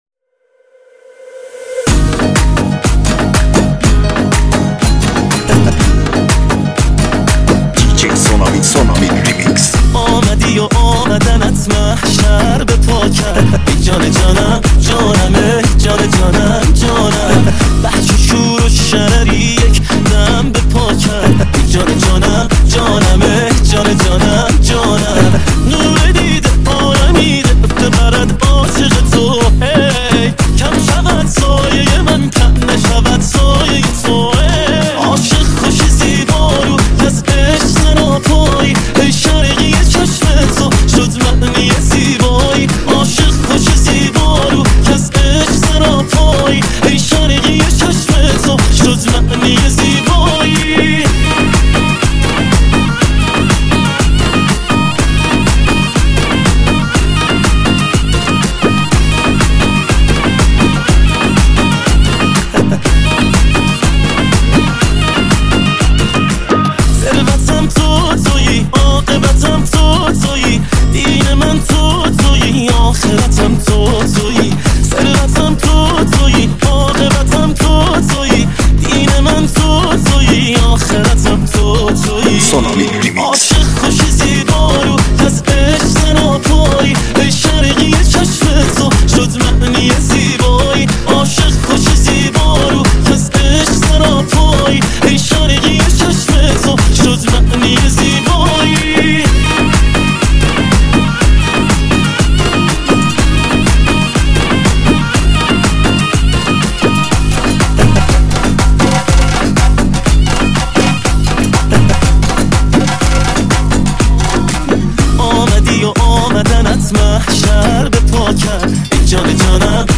ریمیکس
ریمیکس شاد جدید